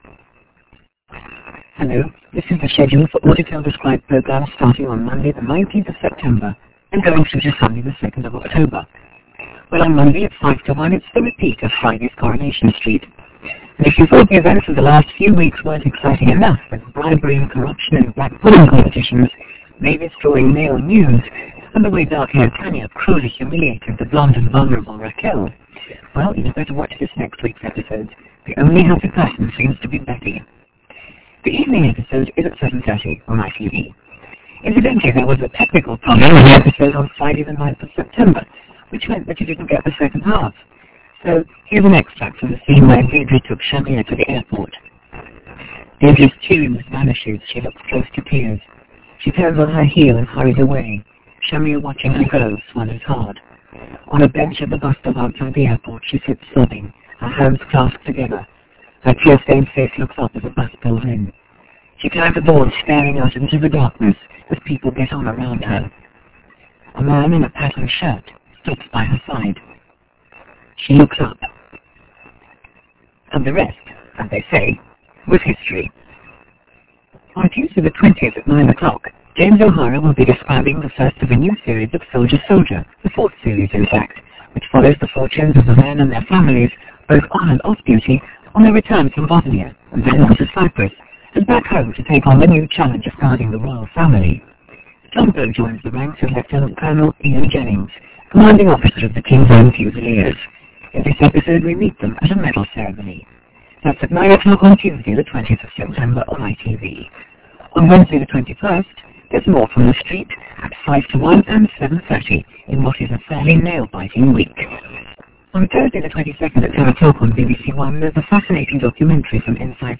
Audio from Teletext (sort-of)
These include mystery audio streams or identifiable Audetel/Auditel streams; an early attempt at audio description.